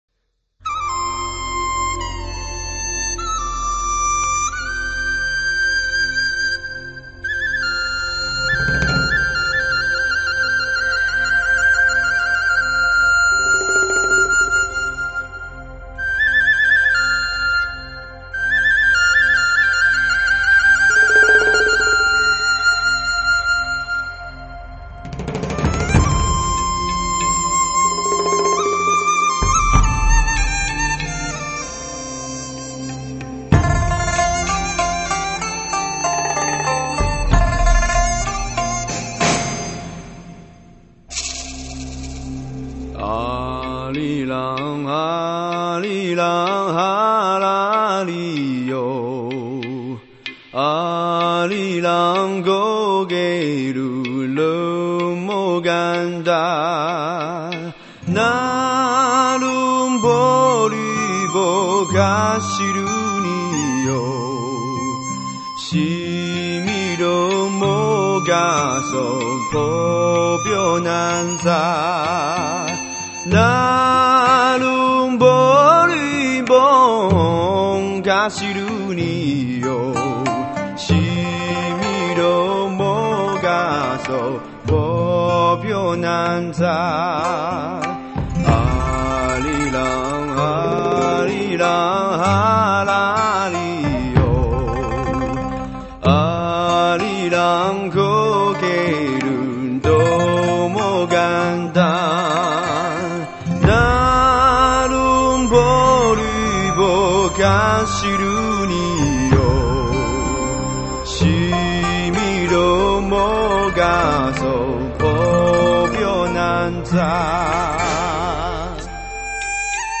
超级的录音 梦幻般磁性的声音